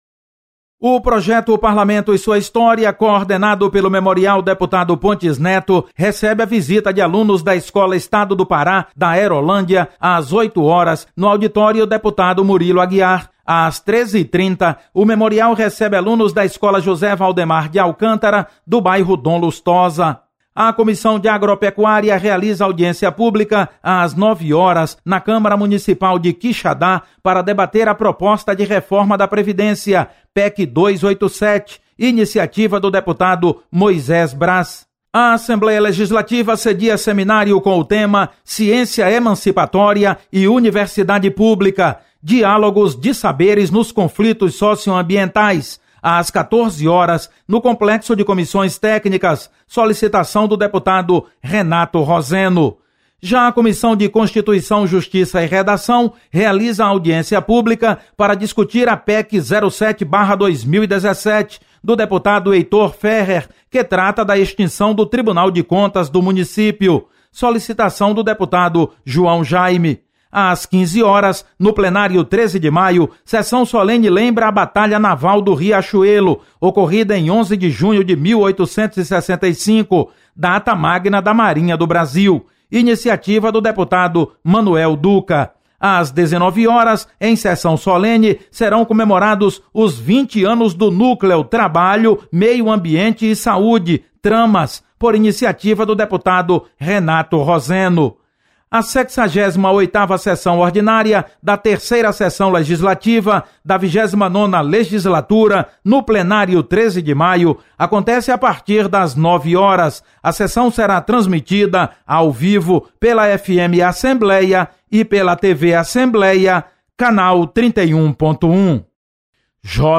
Acompanhe as atividades da Assembleia Legislativa nesta terça-feira. Repórter